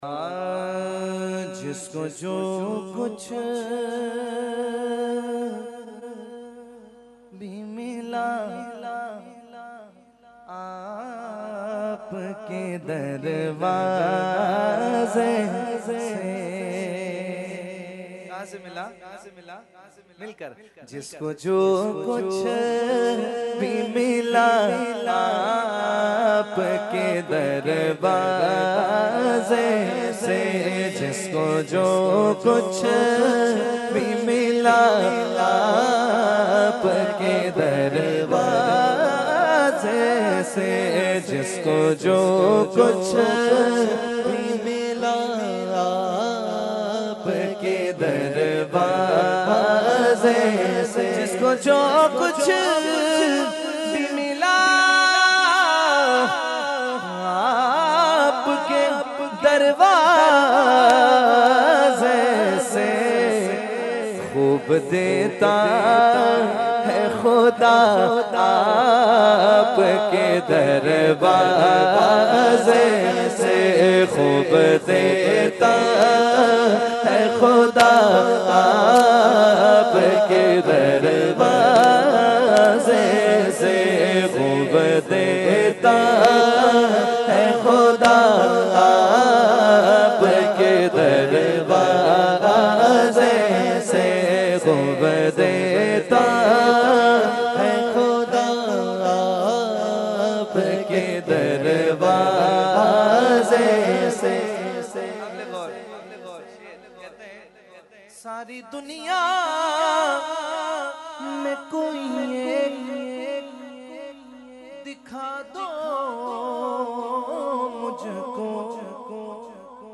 Category : Naat | Language : UrduEvent : Urs Ashraful Mashaikh 2019